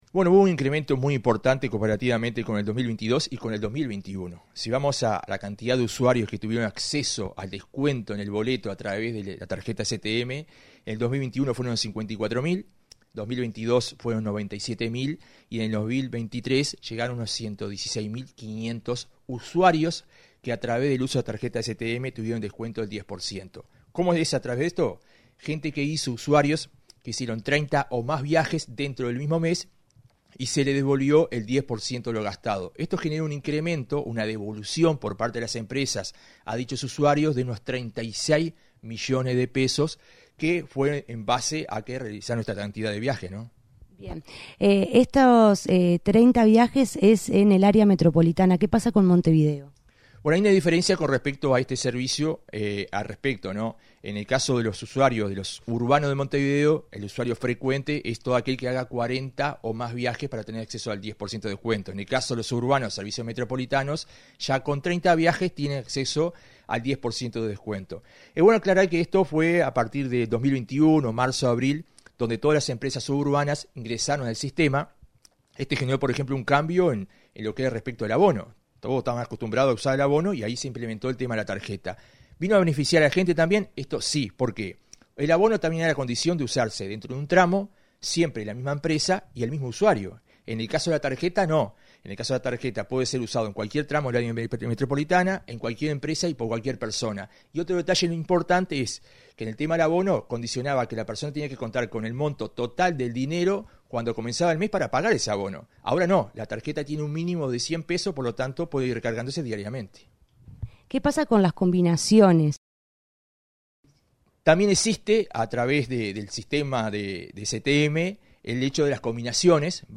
Entrevista al coordinador del área metropolitana del MTOP, Joselo Hernández
El coordinador del área metropolitana del Ministerio de Transporte y Obras Públicas (MTOP), Joselo Hernández, en entrevista con Comunicación